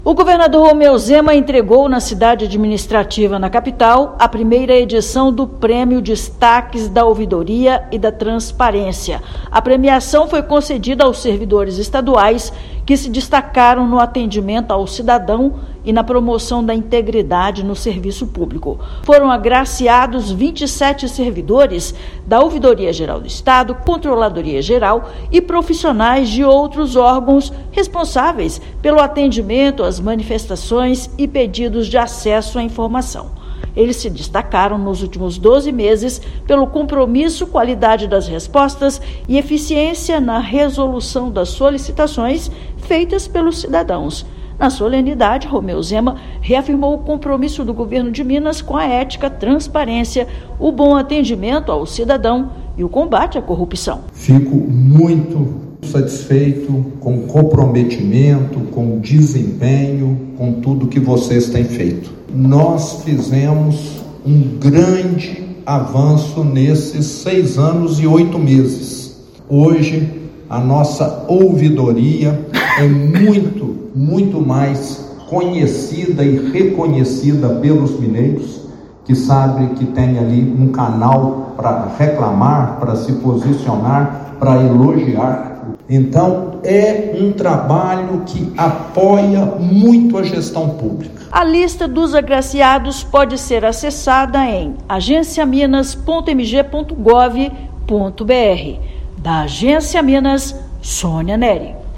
Prêmio Destaques da Ouvidoria e da Transparência valoriza atitudes que fortalecem a máquina pública e contribuem para uma relação mais transparente com a sociedade. Ouça matéria de rádio.